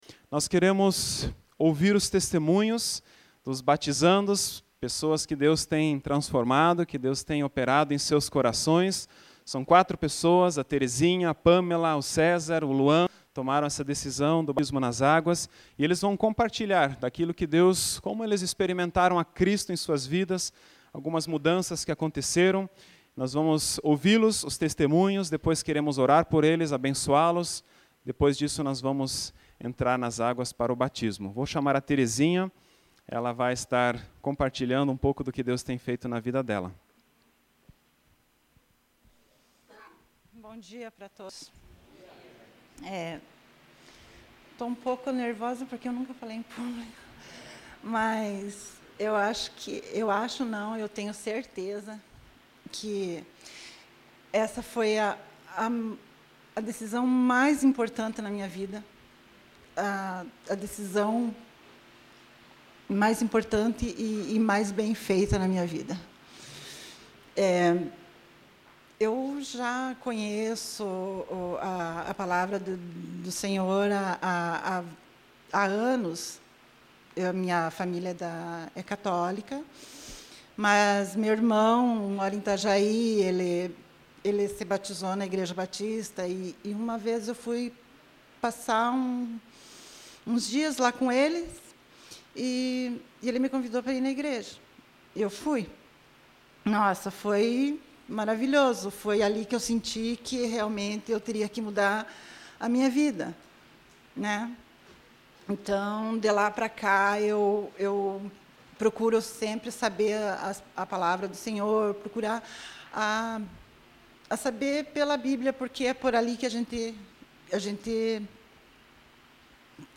Ouça os testemunhos dos batizandos deste domingo - Igreja Evangélica Menonita - Água Verde
Testemunhos de Batismo